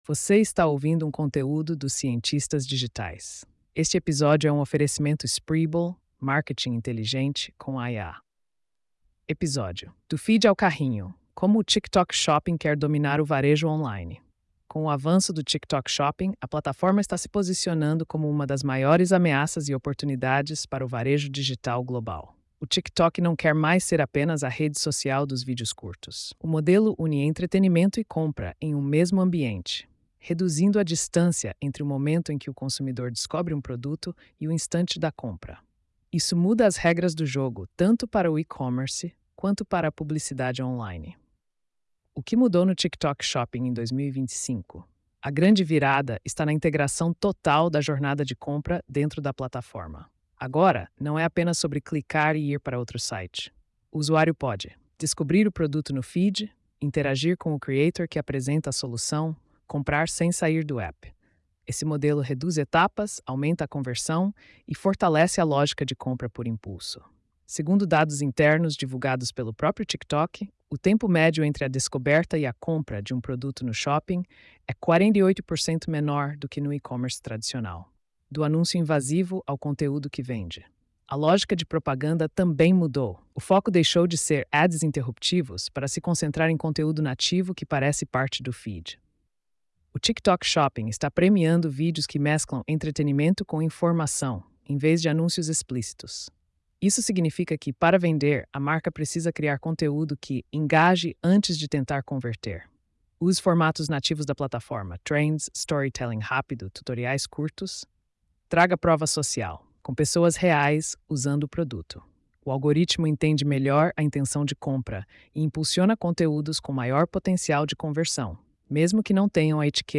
post-4256-tts.mp3